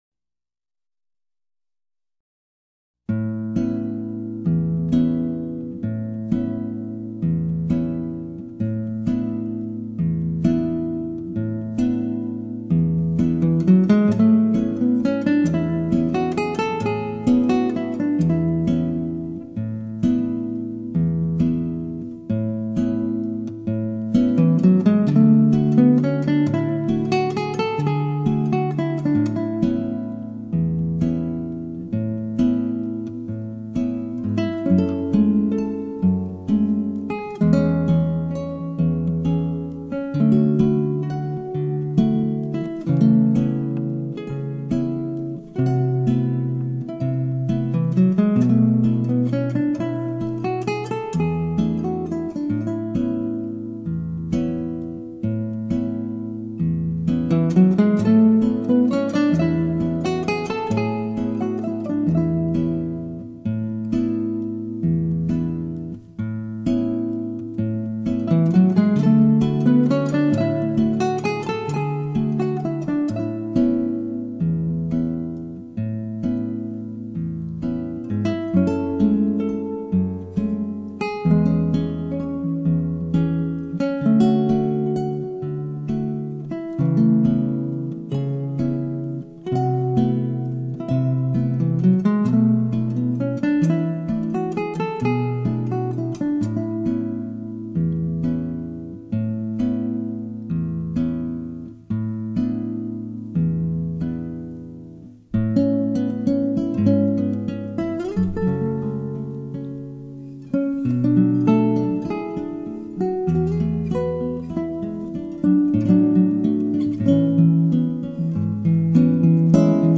飘渺、梦幻般的吉他音色，仿佛进入了美丽天堂，带给了我们一种 全新的感受。